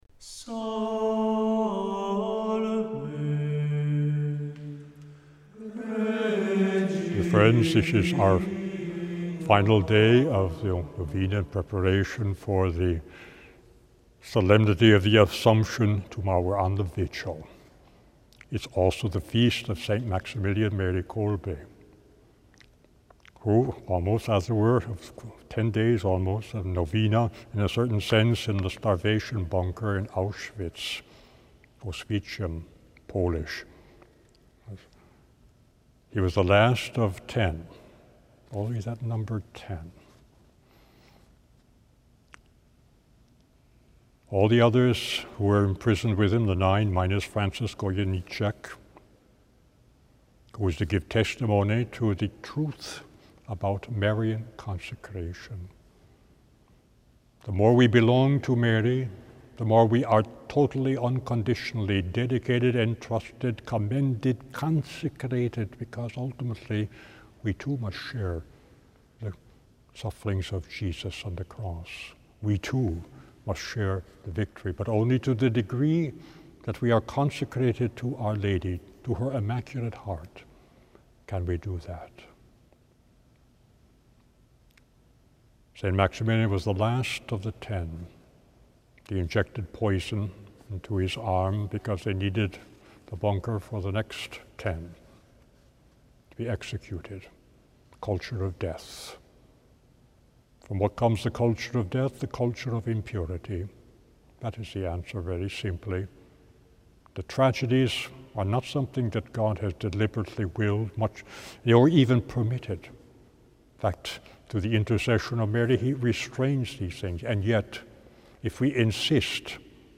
Each day, from the 6th up to the 14th, he gives a short reflection on Our Lady followed by a prayer.